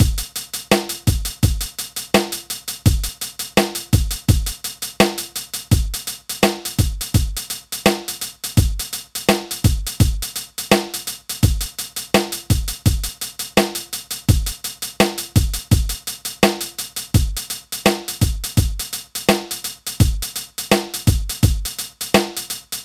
Instrumental für Rap-Text gesucht (ggf. auch Kooperation für mehrere Songs)
an's Tempo angepasst und geshuffelt
4 Takte, danach zwecks Vergleich mit straight eingeklopftem Beat weiter. Und dann noch mit paar Akkorden mehr, in die man aber (s. letzter Kommentar) leider an keiner Stelle gut reinkommt, deshalb einfach mal so angebappt.